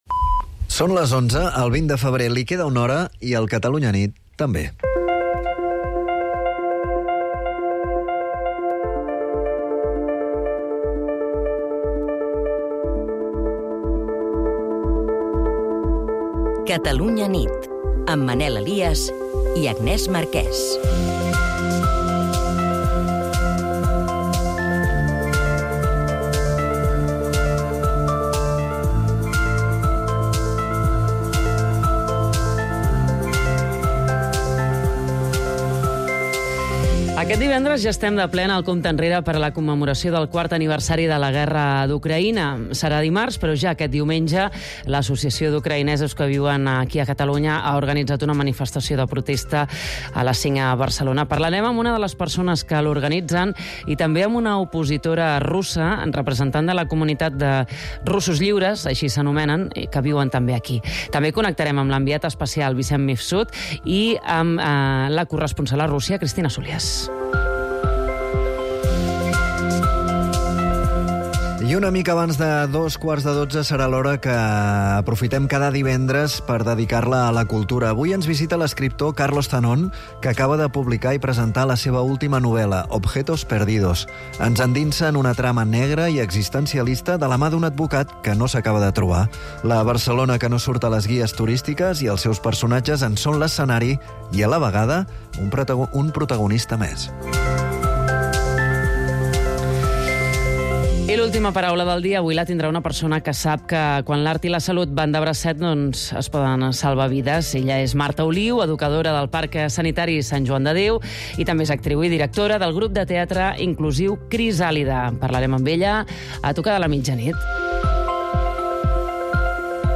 El compromís d’explicar tot el que passa i, sobretot, per què passa és la principal divisa del “Catalunya nit”, l’informatiu nocturn de Catalunya Ràdio, dirigit per Manel Alías i Agnès Marquès.